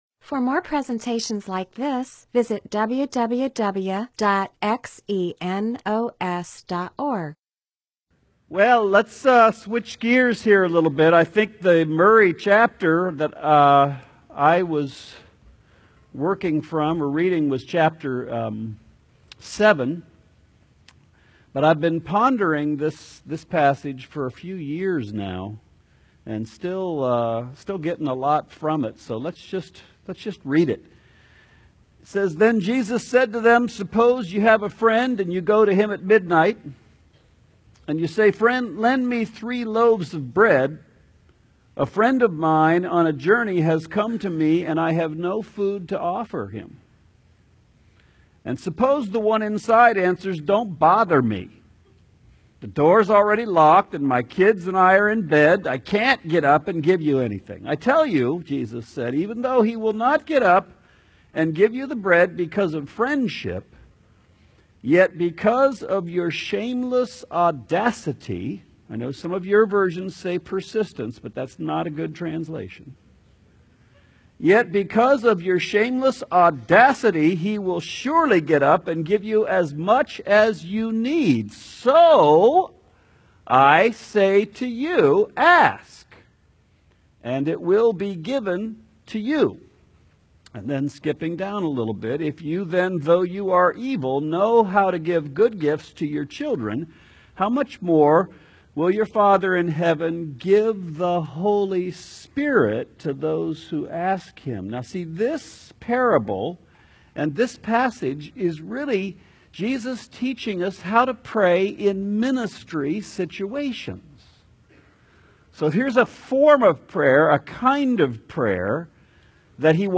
MP4/M4A audio recording of a Bible teaching/sermon/presentation about Luke 11:5-13.